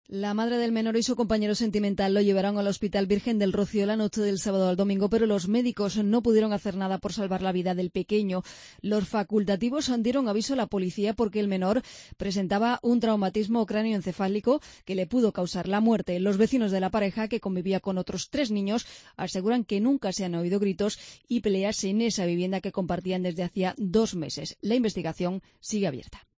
AUDIO: Detenida una pareja en Sevilla por la muerte de un niño de un año. Crónica